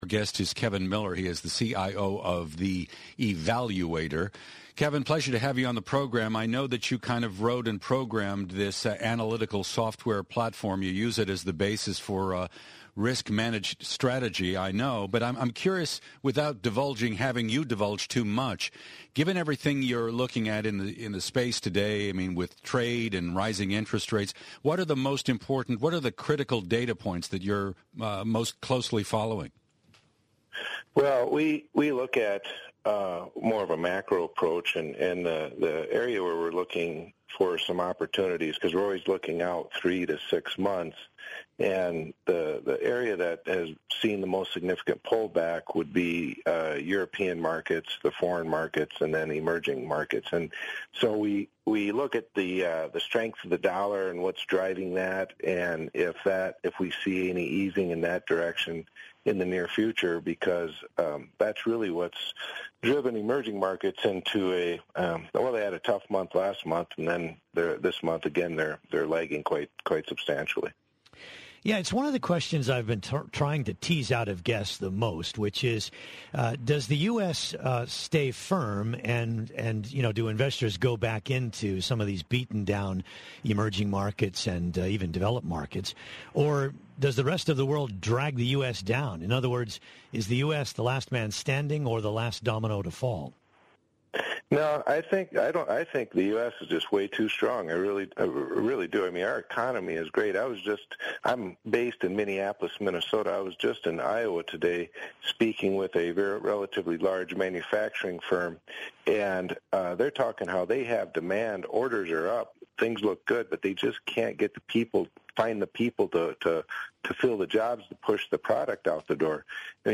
Bloomberg interviews